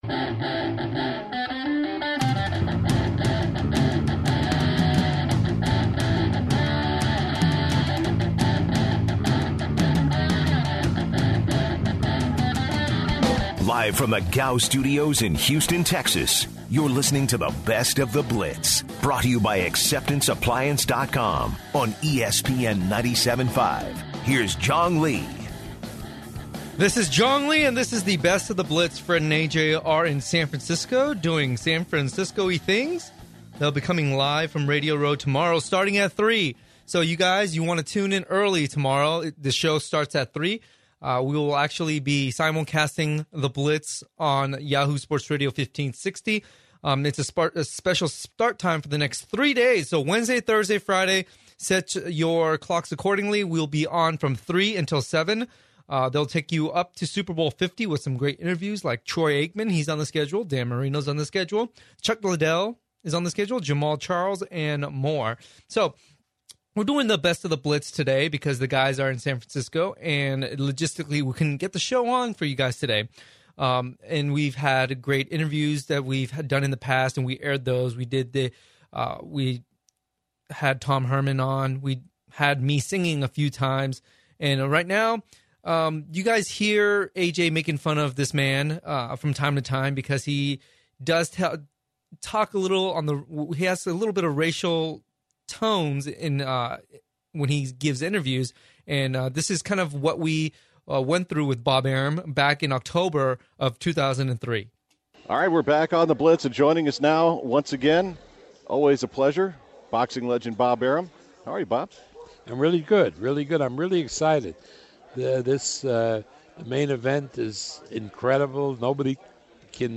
In hour 3 of the Best of the Blitz, the show goes back to when Bob Arum was on the show in 2013 and we hear the past interview with the boxing promoter.